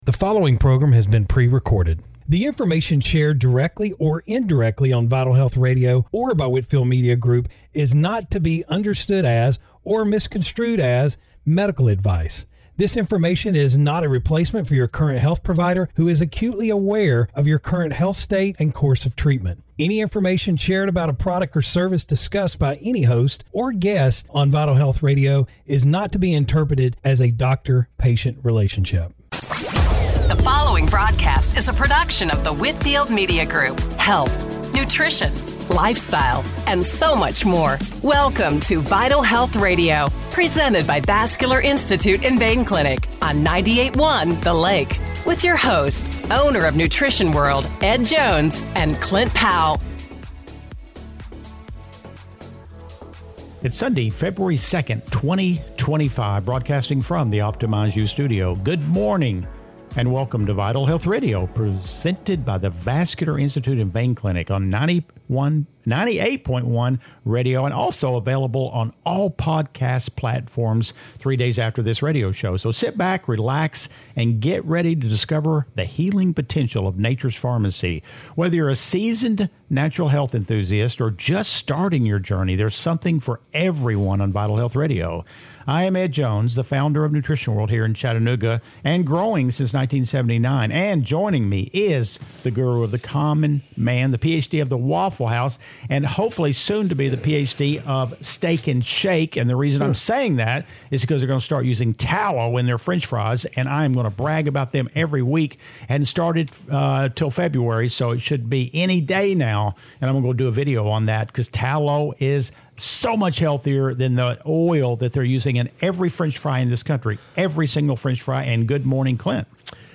Radio Show – February 2, 2025 - Vital Health Radio